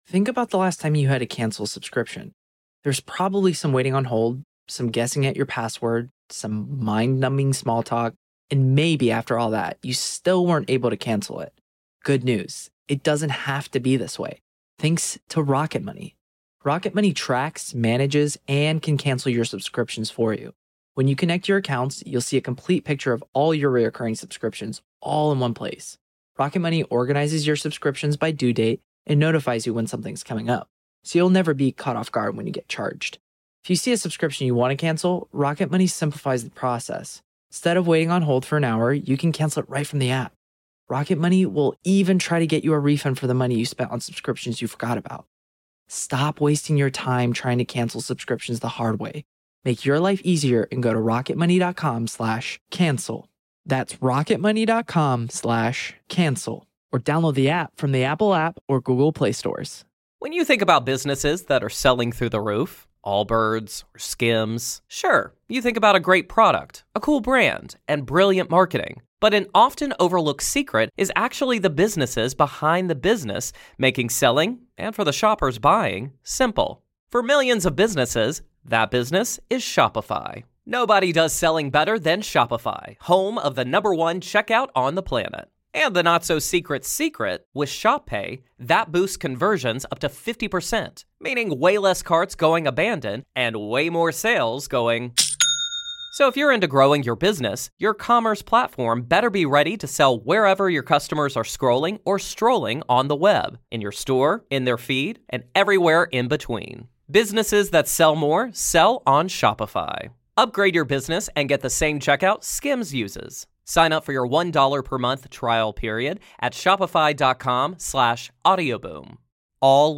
welcomes special guests to take a look at tonight's NBA and MLB action, discuss odds and share picks for The Masters, share bets for tonight's NHL slate, and more!